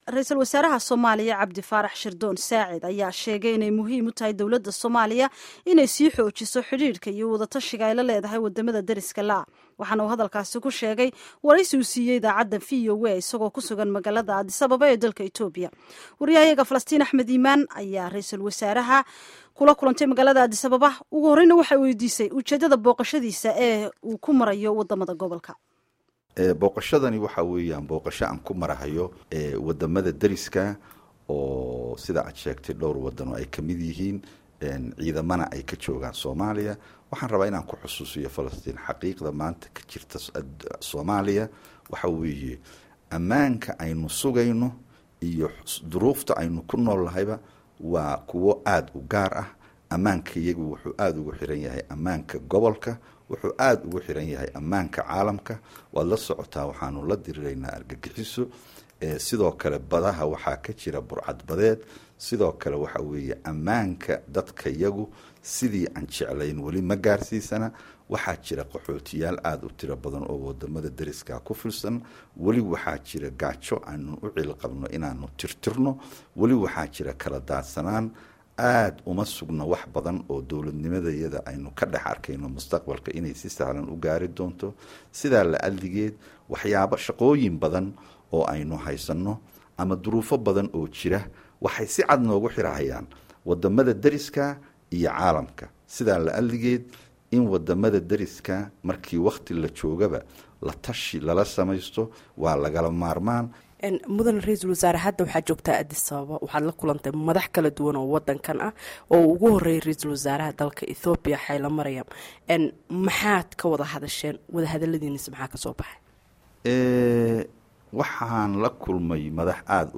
Halkan ka dhageyso Wareysiga Ra'isul Wasaaraha oo Dhameystiran